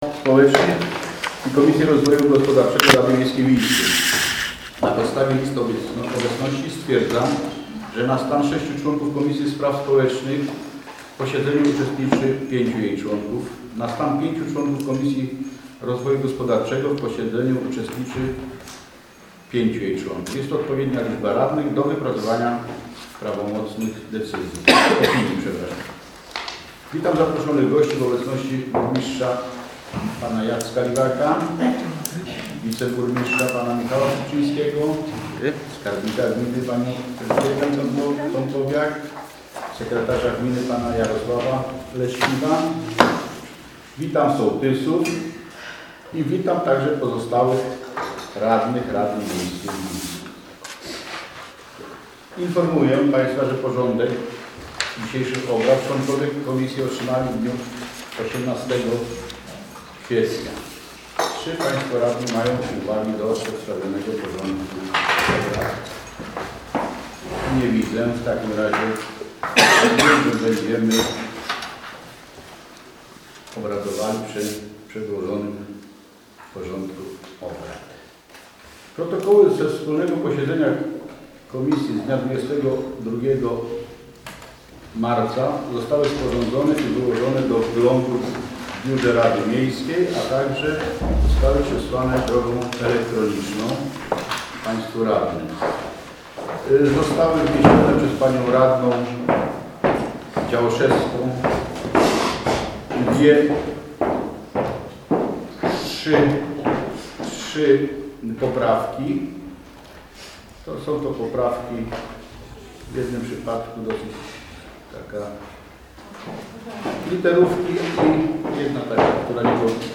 Nagranie ze wspólnego posiedzenia Komisji Rady Miejskiej w Ińsku z 25 kwietnia 2016 roku Strona główna Nagranie ze wspólnego posiedzenia Komisji Rady Miejskiej w Ińsku z 25 kwietnia 2016 roku komisje_25.04.2016.mp3